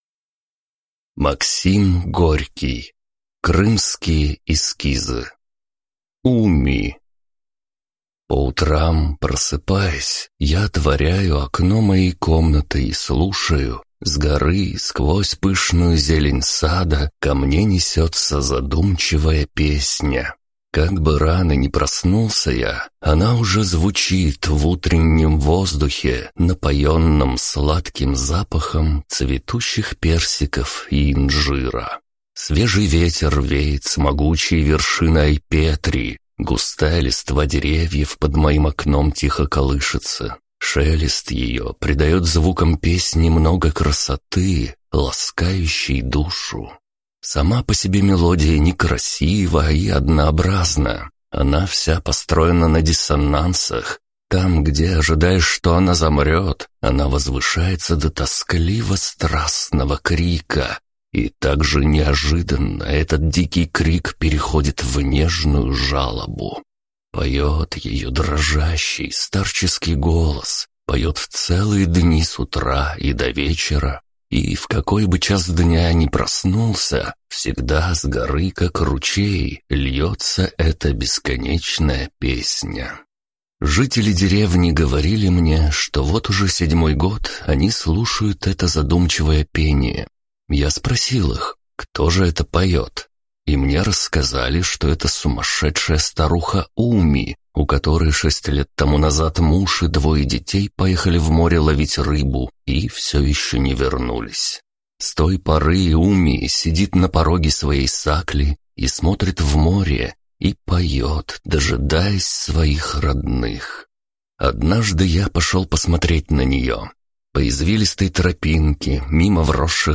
Аудиокнига Крымские эскизы | Библиотека аудиокниг